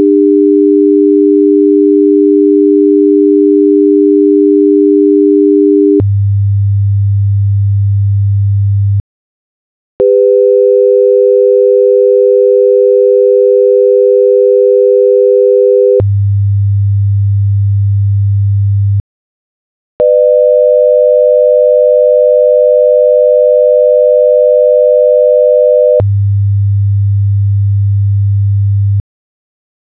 300 Hz/ 400 Hz
400 Hz/ 500 Hz
500 Hz, 600Hz
All of them have the same fundamental frequency of 100 Hz.